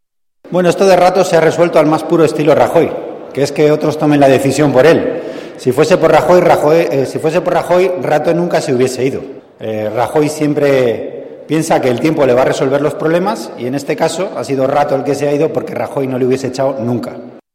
Declaraciones de Antonio Hernando en el Congreso sobre Rodrigo Rato 21/10/2014